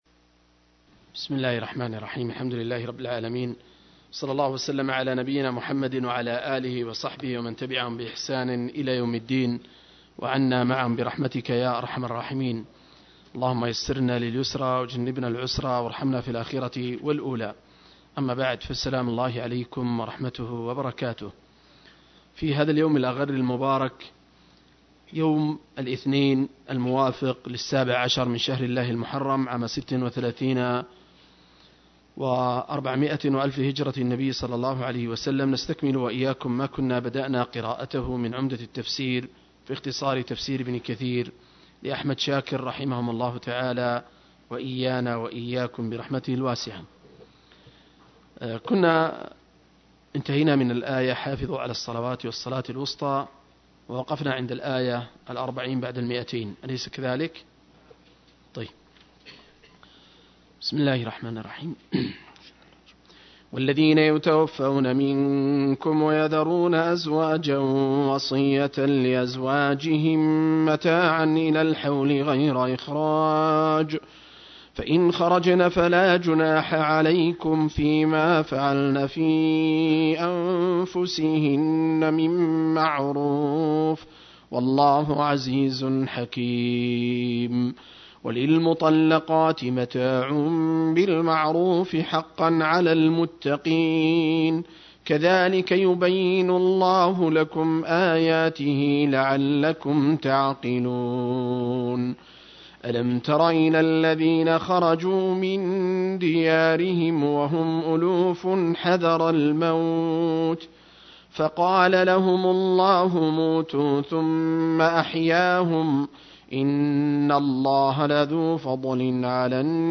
المجلس الخمسون: تفسير سورة البقرة (الآيات 245-240)